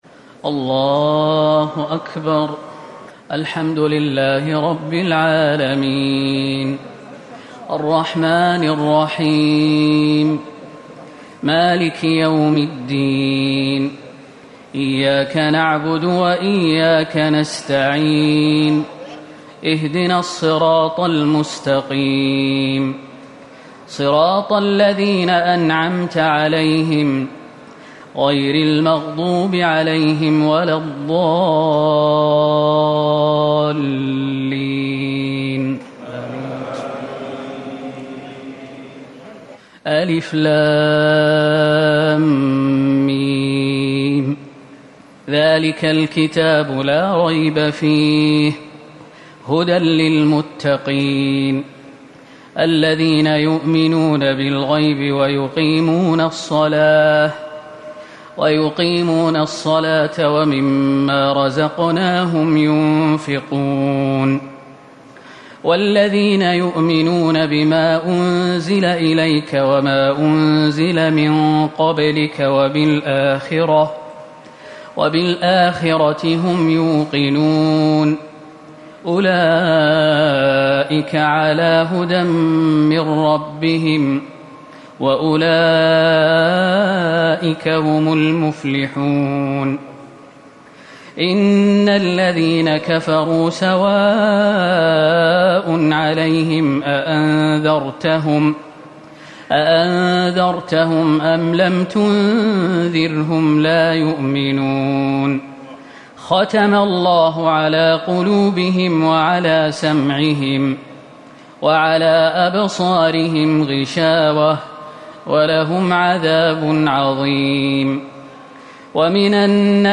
تهجد ٢١ رمضان ١٤٤٠ من سورة البقرة ١-٩١ > تراويح الحرم النبوي عام 1440 🕌 > التراويح - تلاوات الحرمين